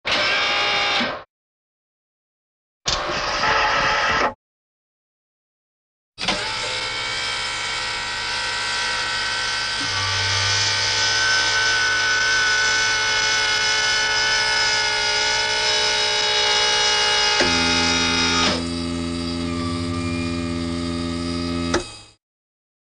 Звуки механизма робота
Все аудиофайлы записаны с реальных устройств, что придает им естественность.
Механизмы робота производят звуки